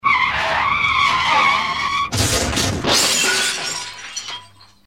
Визг тормозов